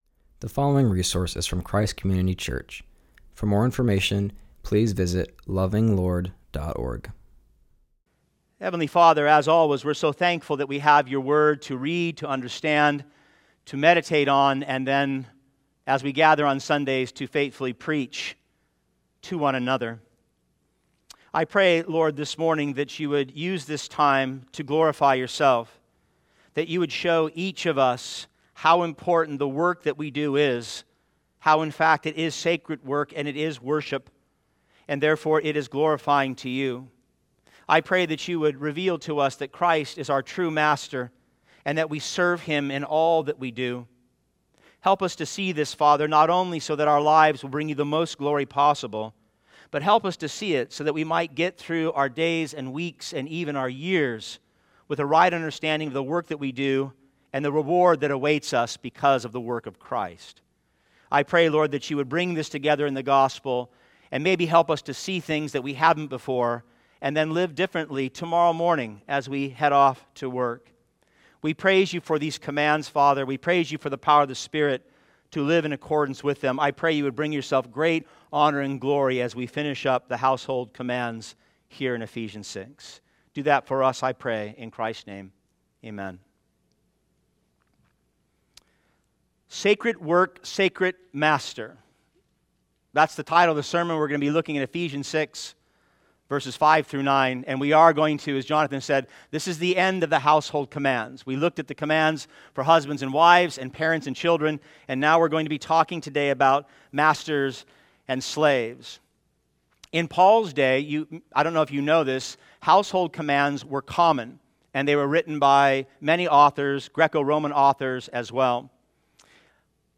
continues our series and preaches from Ephesians 6:5-9.